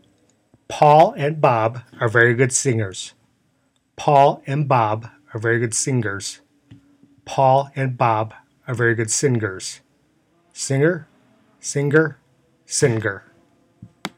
here is my attempt to show the difference between "/ˈsɪŋgər/, /ˈsɪŋər/ and /ˈsɪngər/, in that order. There is also the difference between the “aw” in “Paul” and the “ah” in “Bob.”
The third may be harder to hear, but I am saying “sin-ger.”